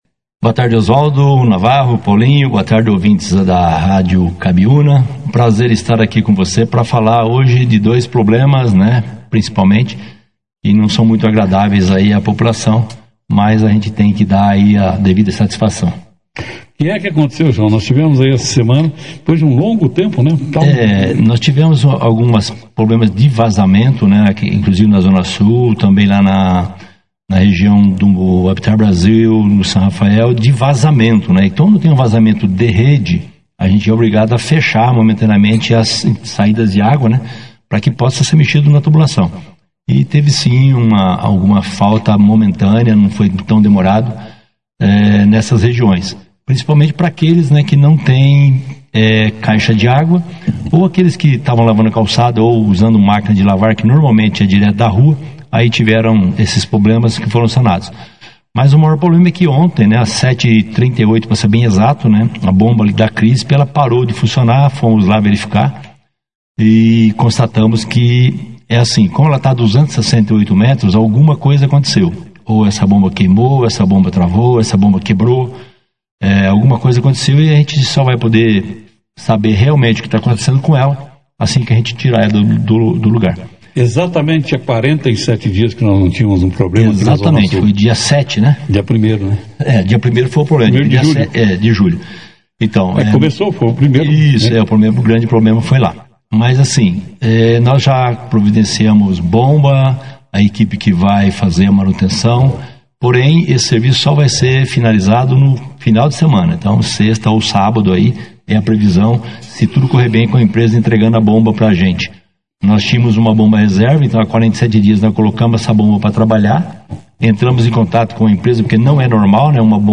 Diretor do SAAE explica falta de água na Zona Sul e protesto para inadimplentes - Rádio Cabiuna
O diretor do SAAE de Bandeirantes, João Guin Filho, (foto), participou da 2ª edição do Jornal Operação Cidade nesta segunda-feira (18) para falar sobre dois assuntos que preocupam os moradores: